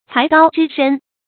材高知深 注音： ㄘㄞˊ ㄍㄠ ㄓㄧˋ ㄕㄣ 讀音讀法： 意思解釋： 材：通「才」。